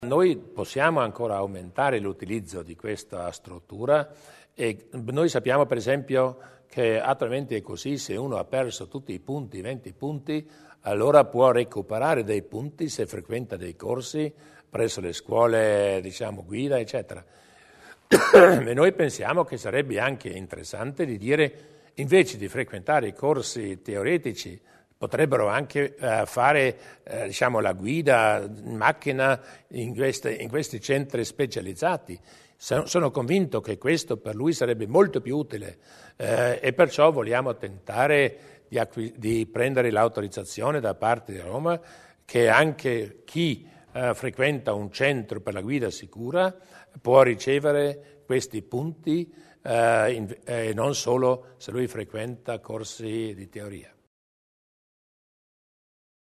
Il Presidente Durnwalder sul futuro del Safety-Park